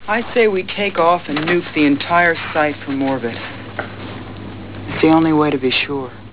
bomb.au